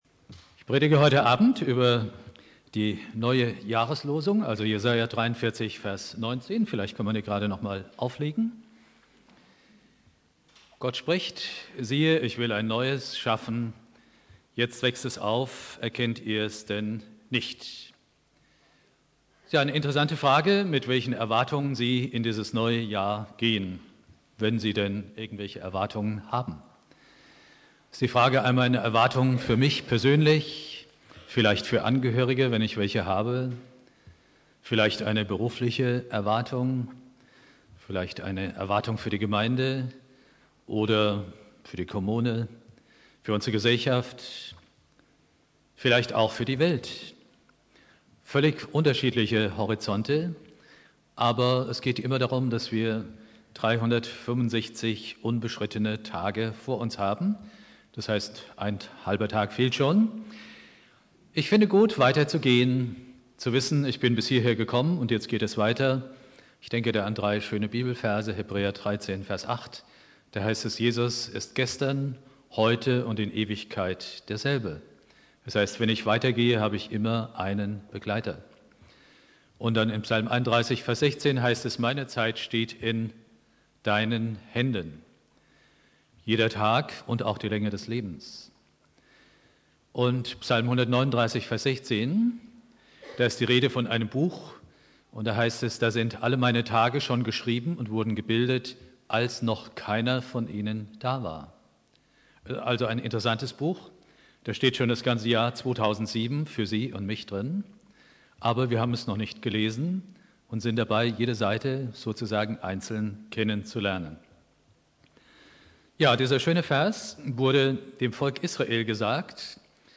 Predigt
Neujahr Prediger